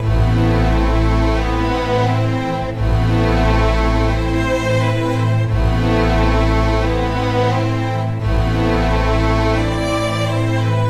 合成器垫循环（d小调）
描述：D小调的合成短语。在现场表演中循环使用多次作为垫，具有延迟的苦涩纹理。
标签： 低频 电子 d小调 合成器 循环 无人驾驶飞机 回波 免费 环境 进展 VST 声音 德拉
声道立体声